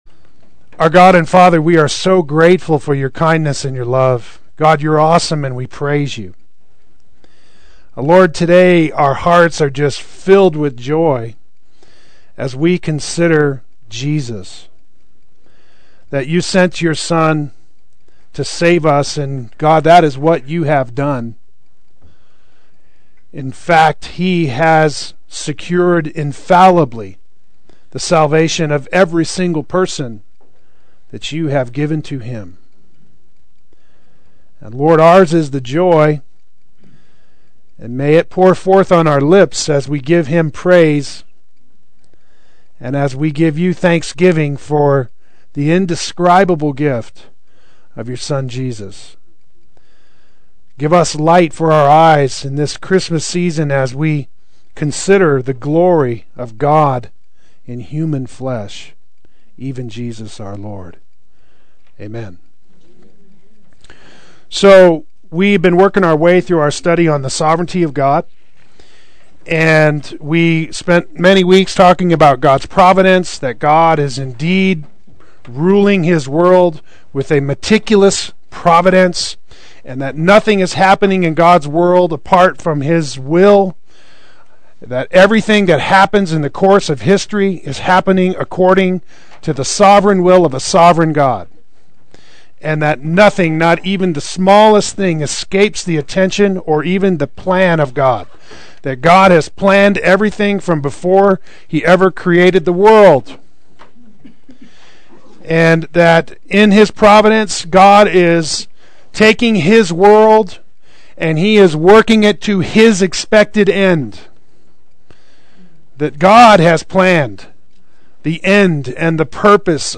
God’s Glory and the Plan of Redemption Adult Sunday School